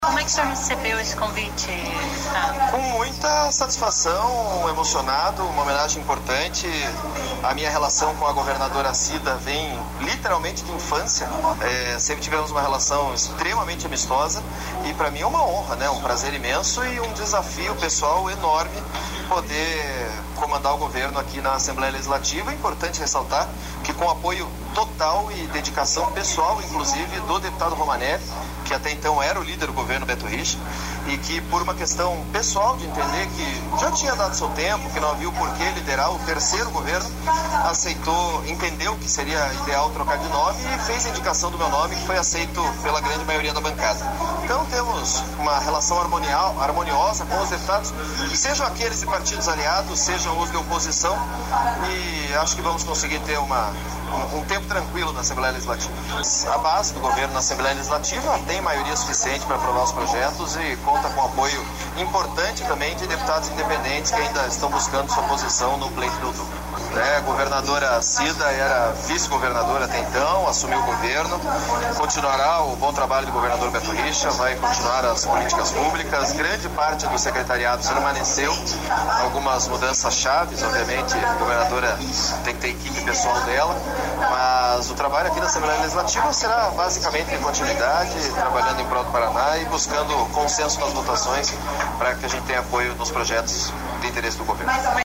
O novo líder do Governo diz que tem aval de Romanelli, líder na gestão de Beto richa (PSDB). Ouça entrevista.